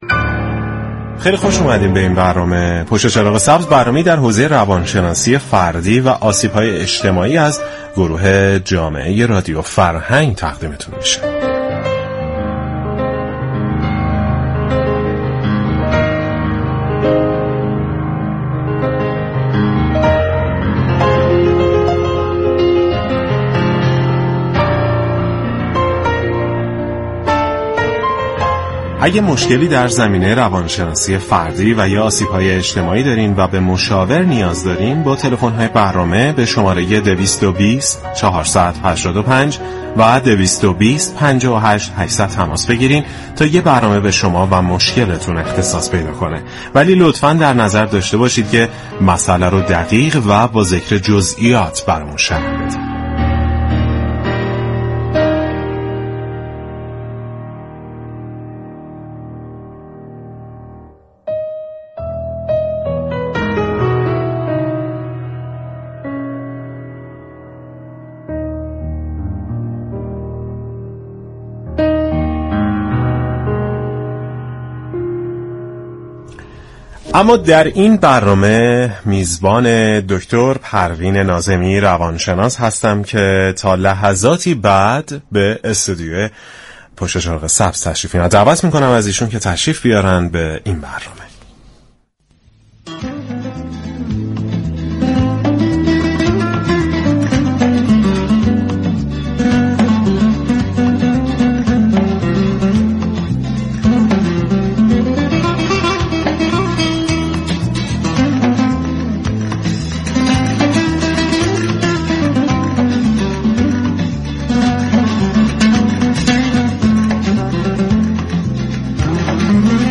شنونده ای در تماس با برنامه گفت : چندسالی است با رسیدن فصل پاییز دچار بی حوصلگی می شوم و بهره وری و كارایی ام پایین آمده و بیشتر ساعتهای روز را به خواب سپری میكنم .